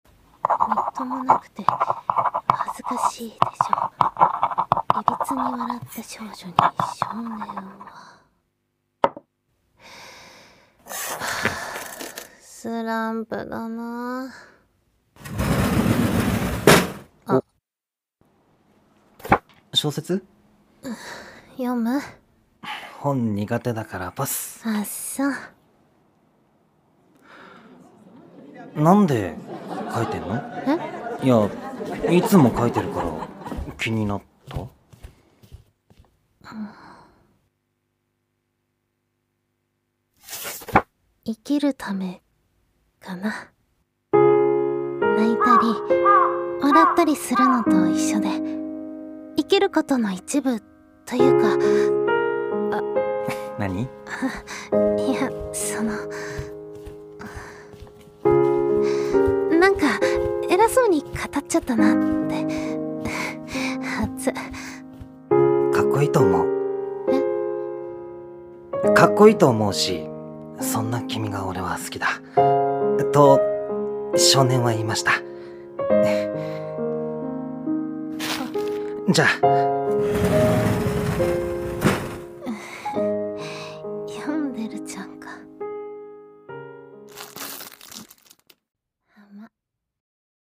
二人声劇】ちろるちょこれゐと